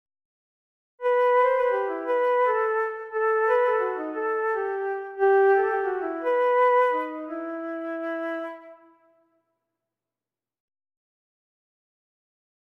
lecture chantée - complet